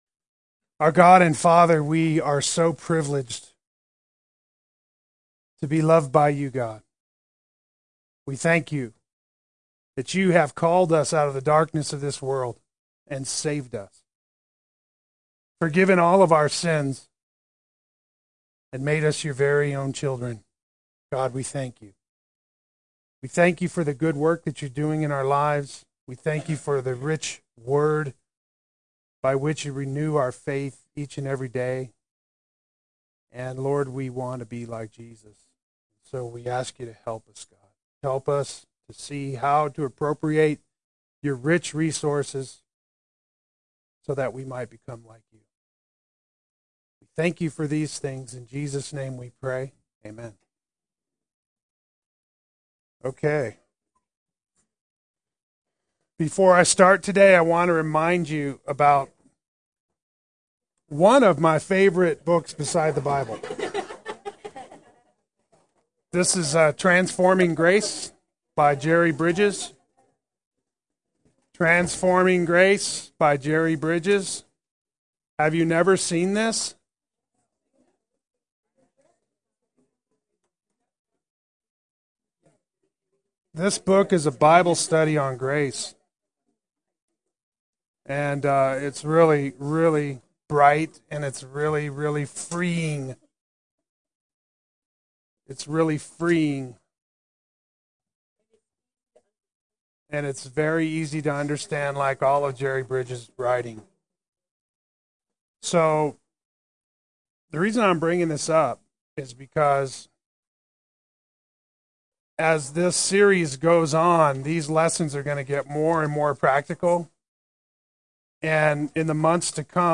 Play Sermon Get HCF Teaching Automatically.
The Cross and Our Failures Adult Sunday School